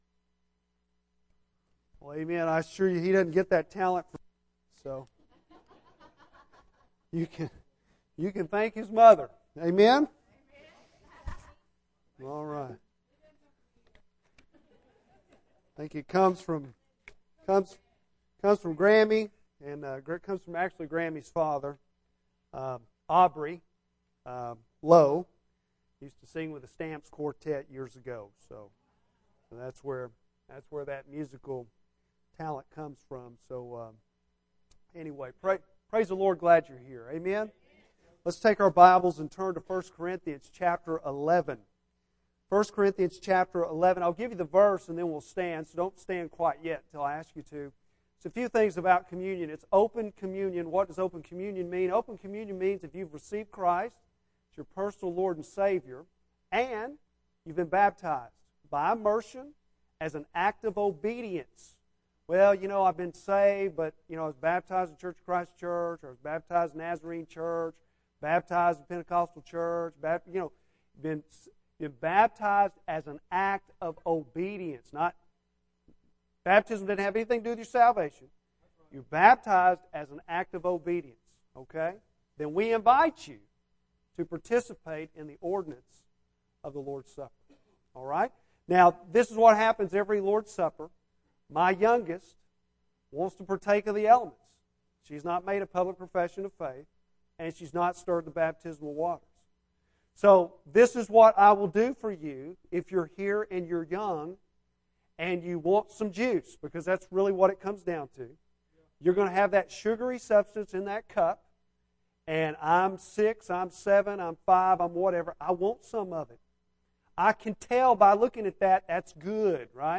Bible Text: Jeremiah 18:1-6 | Preacher